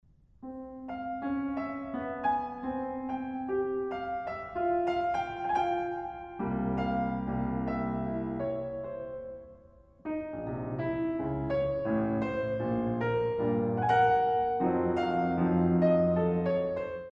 in F Minor